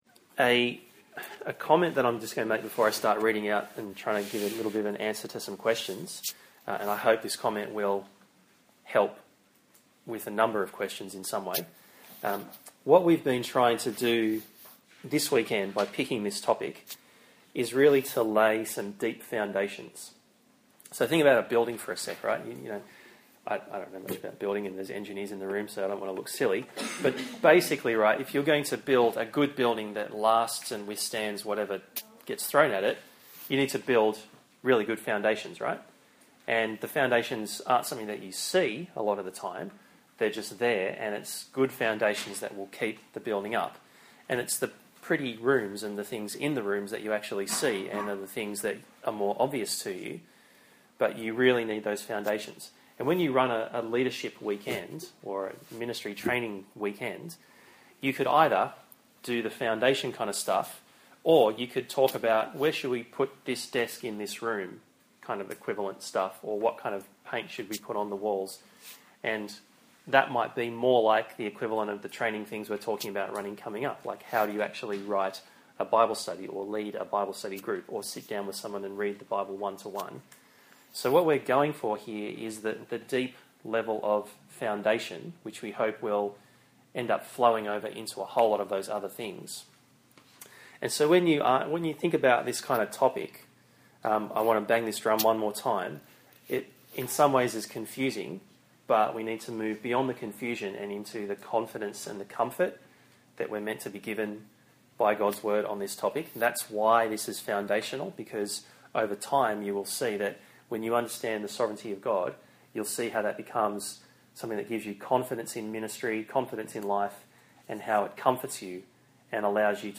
Last month, Christian Union held its annual leadership training conference, Relay. This year's conference was called 'Our Sovereign Saviour: How the doctrine of Predestination shapes our approach to ministry.'
relay-q-a-talk-5.mp3